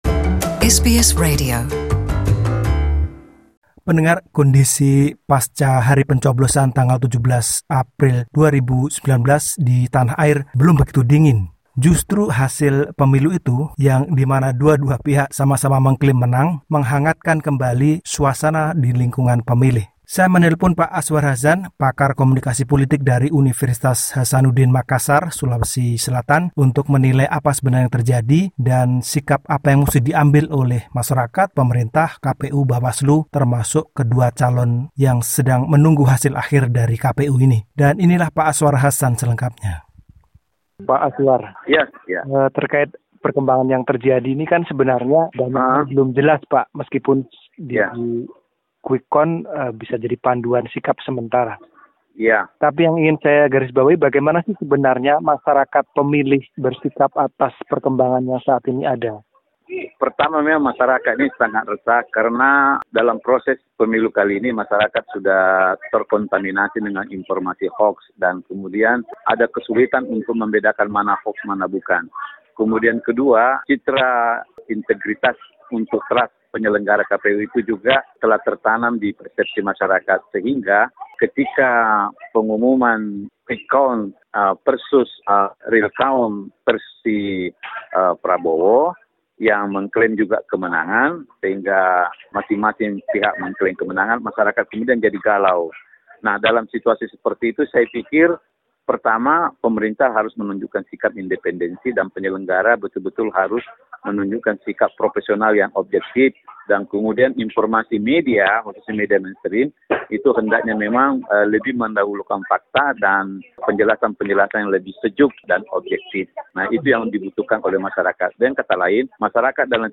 Berikut wawancara dengan pakar komunikasi politik dari Universitas Hasanuddin